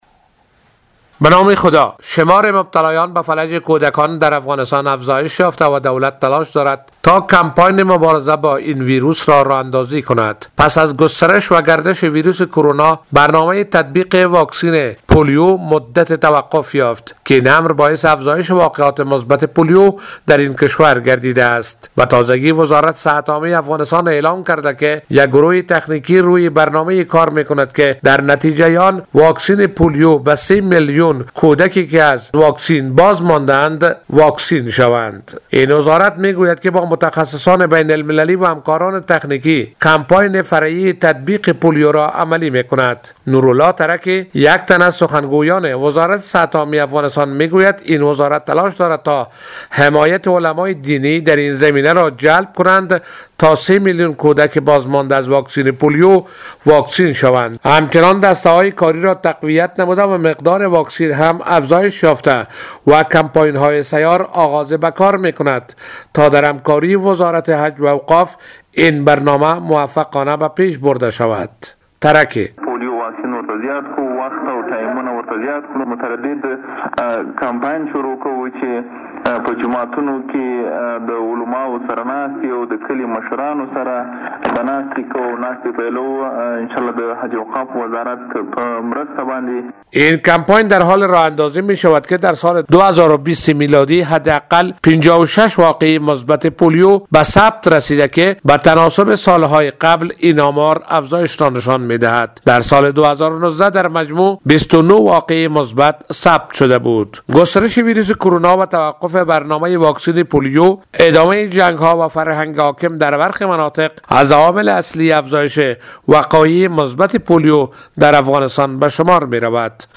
خبر